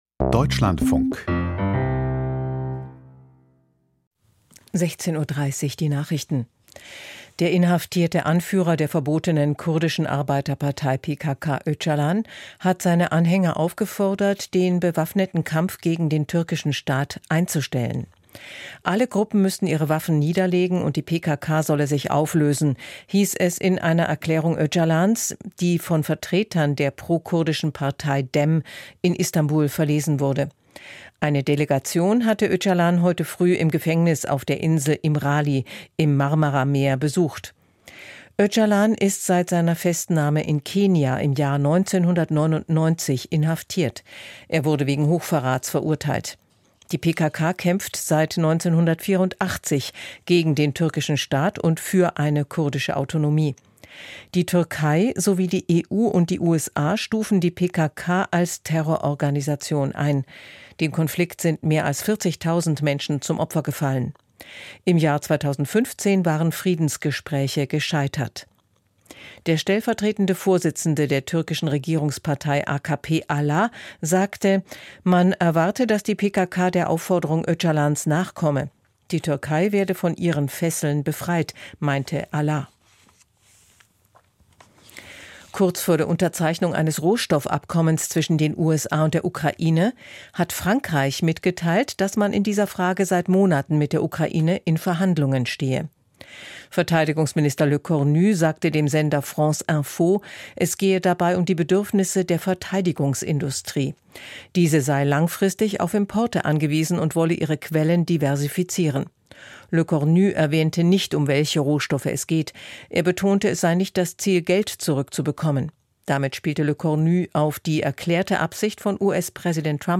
Die Deutschlandfunk-Nachrichten vom 27.02.2025, 16:30 Uhr